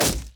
Spell Impact 1.ogg